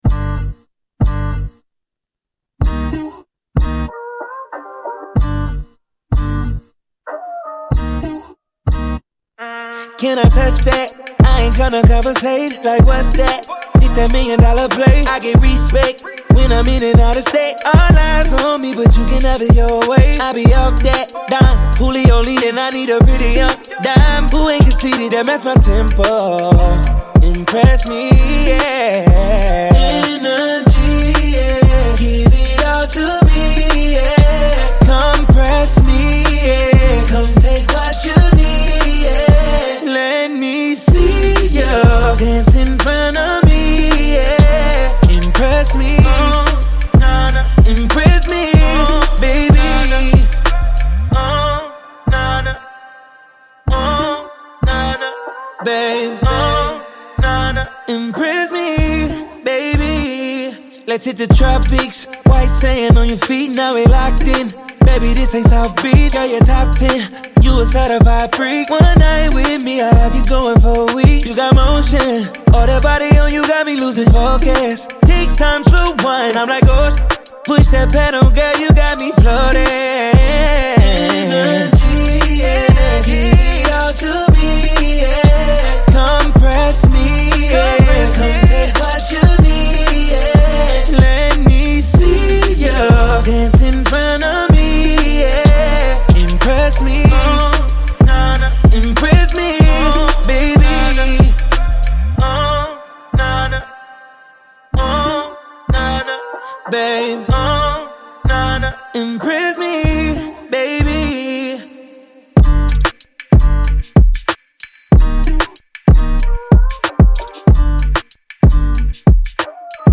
R&B, pop, and smooth beats